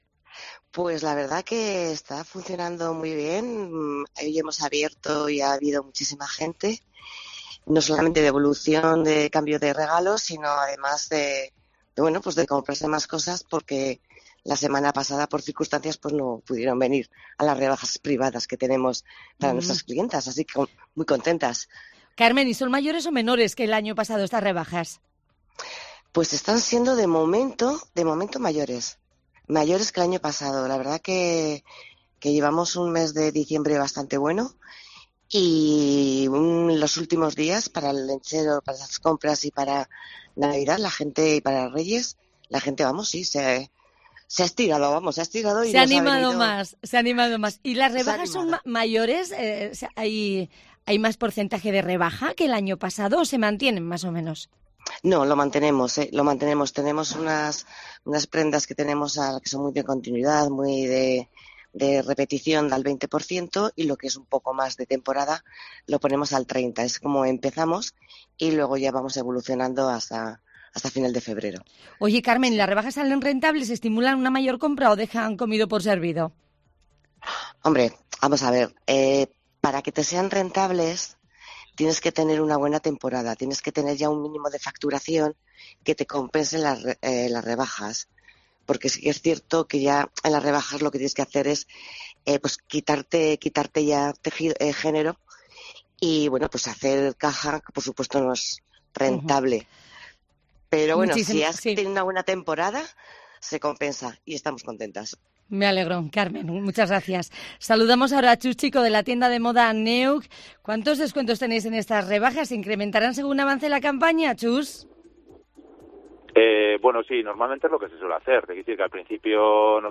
COPE Euskadi ha salido a la calle a ver cuántos descuentos hay en estas rebajas de invierno que se prolongarán hasta el 29 de febrero.